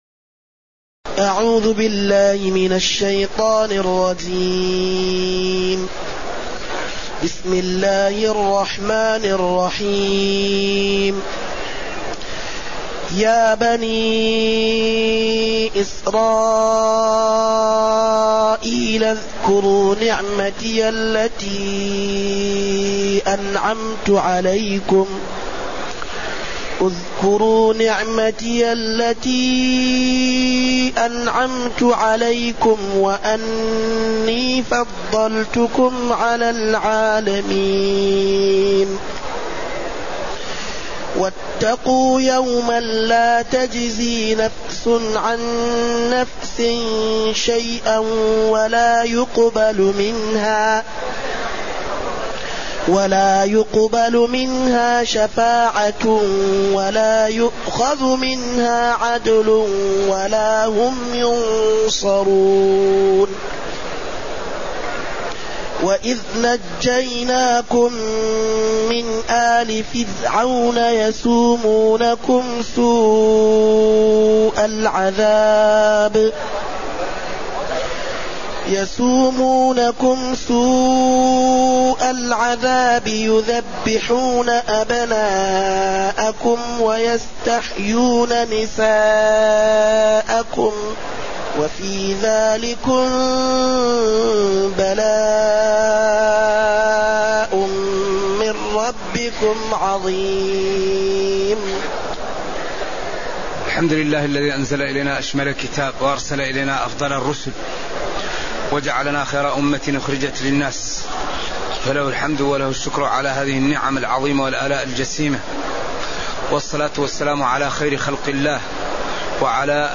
تاريخ النشر ٥ محرم ١٤٢٨ هـ المكان: المسجد النبوي الشيخ